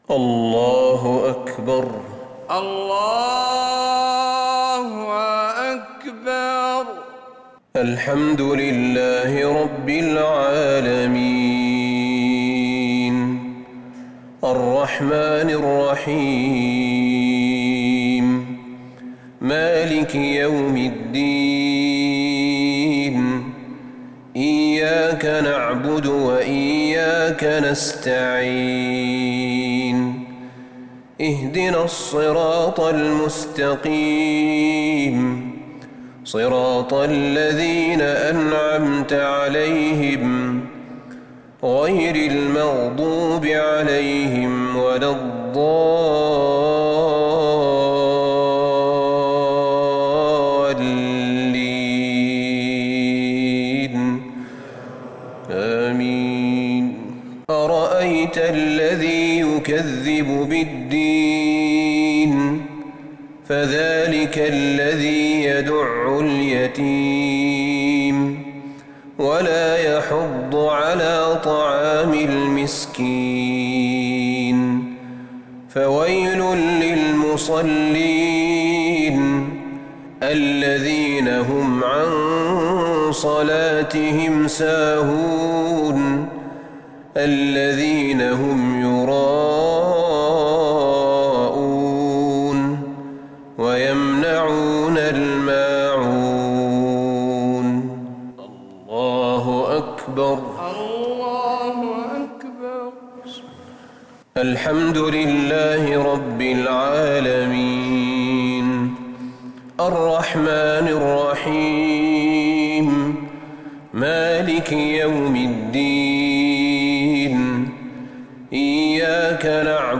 صلاة المغرب للشيخ أحمد بن طالب حميد 3 ربيع الأول 1442 هـ
تِلَاوَات الْحَرَمَيْن .